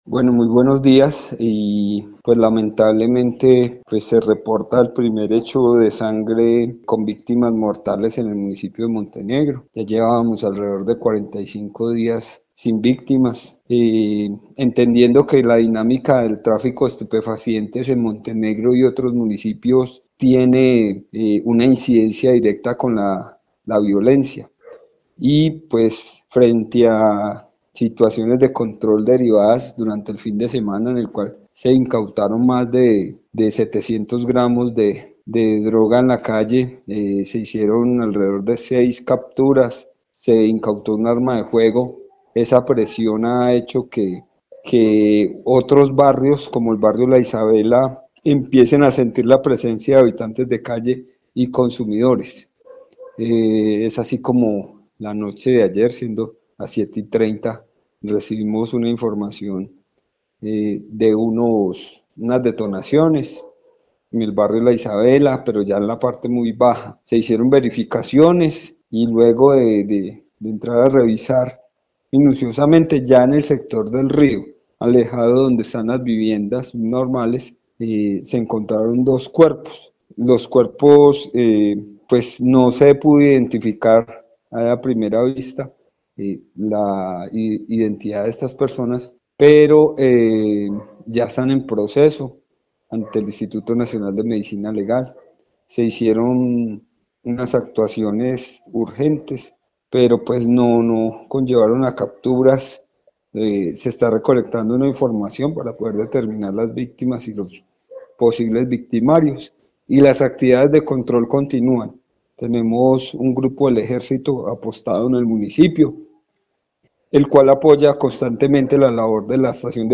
Secretario de Gobierno de Montenegro, Mauricio Cardenas